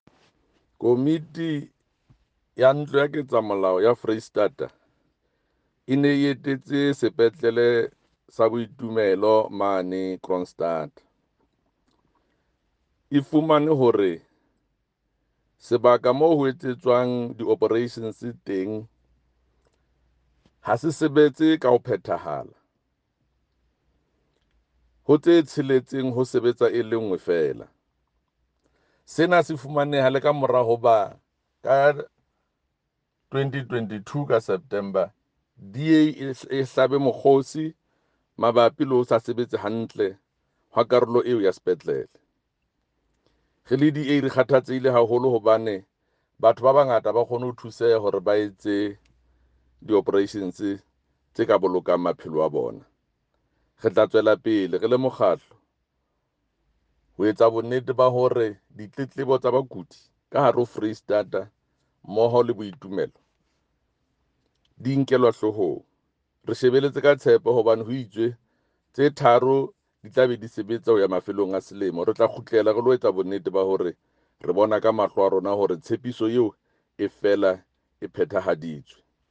Sesotho by Jafta Mokoena MPL.
Sotho-voice-Jafta-10.mp3